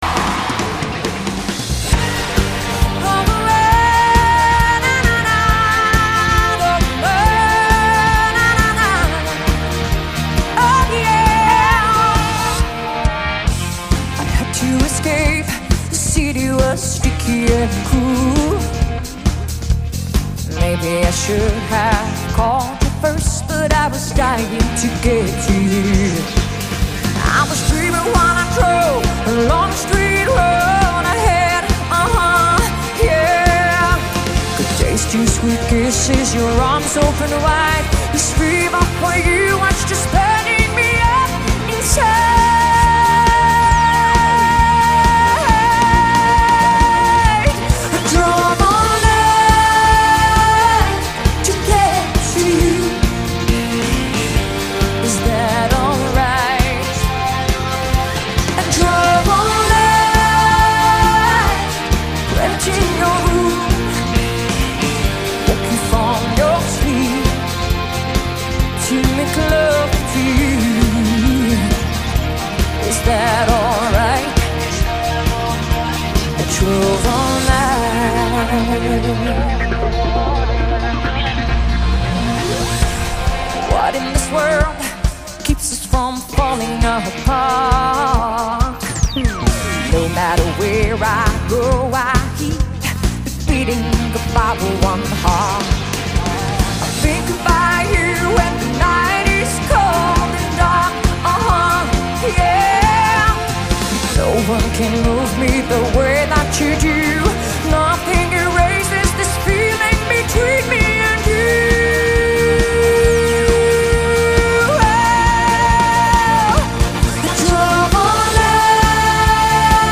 音乐风格: Pop/流行